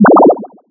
alien_die_01.wav